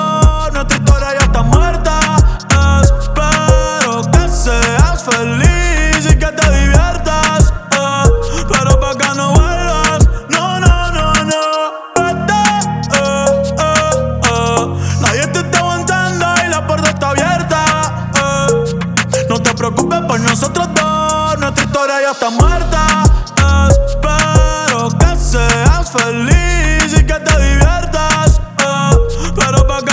Genre: Urbano latino